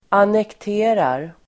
Uttal: [anekt'e:rar]
annekterar.mp3